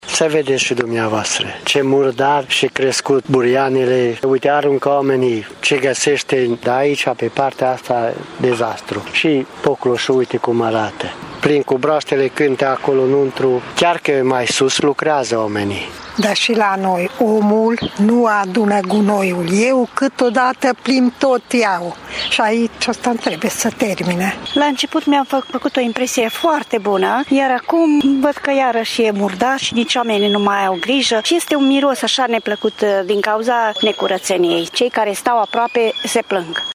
Târgumureșenii care se plimbă pe aleile prâului sunt nevoiți de multe ori să-și țină respirația: